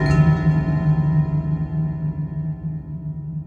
Ambient Strum.wav